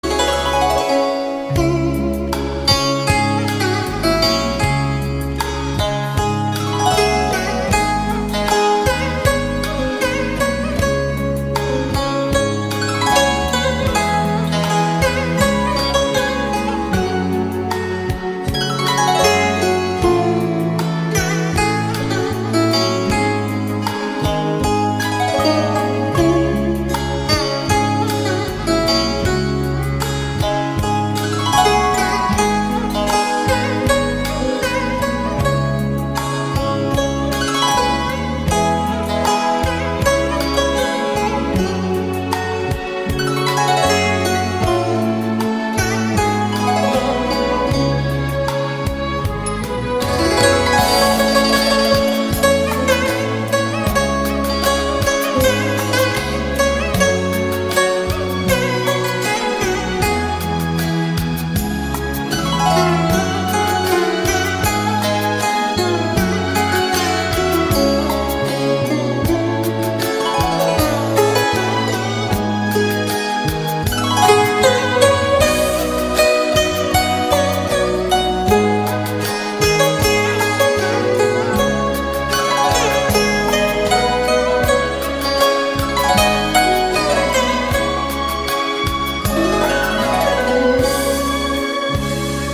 giai điệu nhẹ nhàng và sâu lắng.